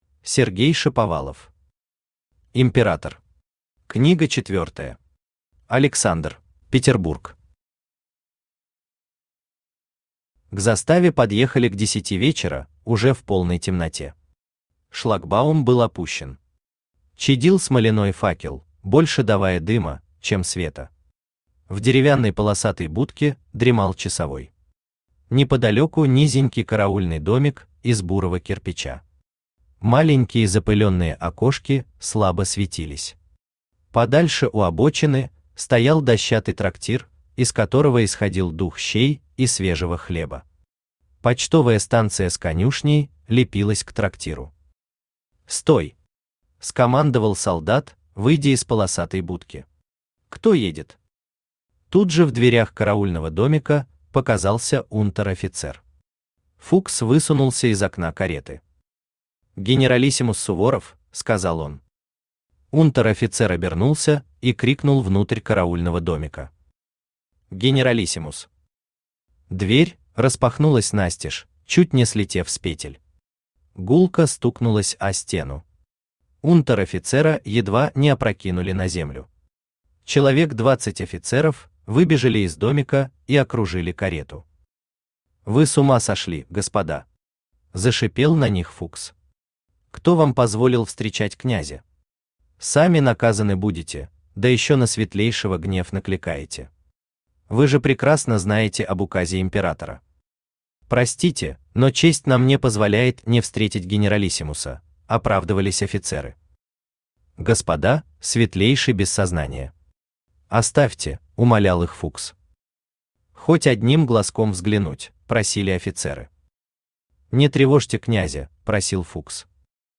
Аудиокнига Император. Книга четвертая. Александр | Библиотека аудиокниг
Александр Автор Сергей Анатольевич Шаповалов Читает аудиокнигу Авточтец ЛитРес.